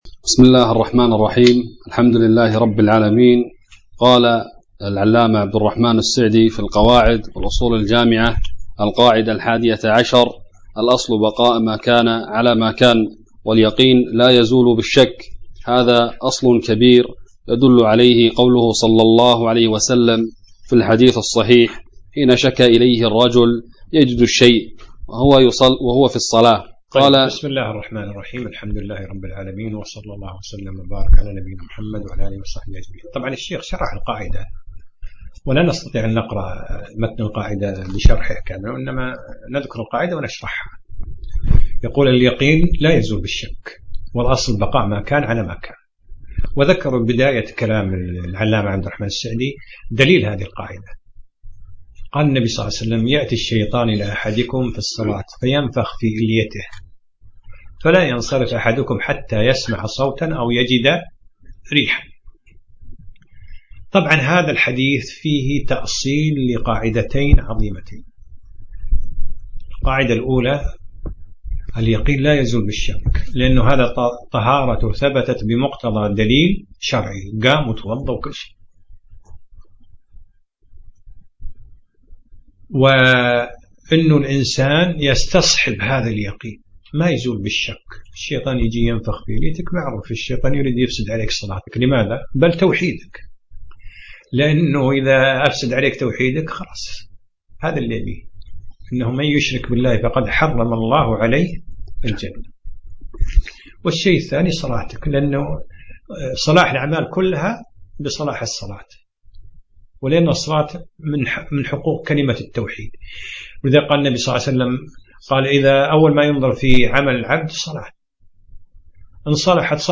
الدرس الأول : من القاعدة 11